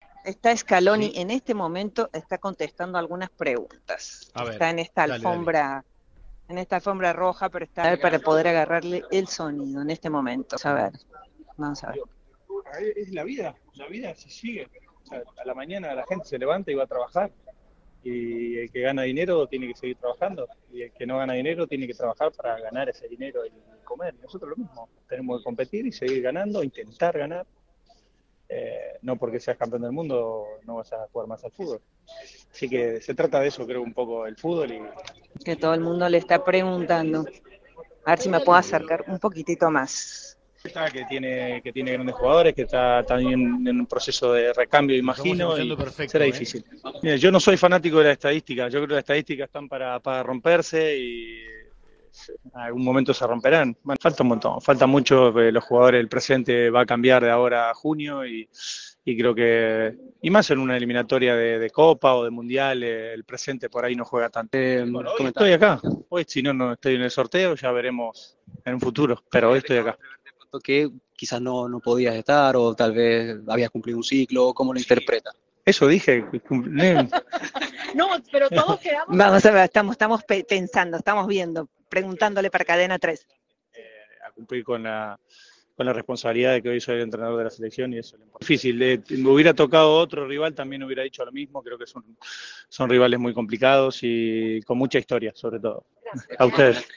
“Hoy estoy acá; ya veremos en un futuro”, dijo a Cadena 3 en rueda de prensa.
Audio. Scaloni habló sobre su continuidad tras el sorteo de la Copa América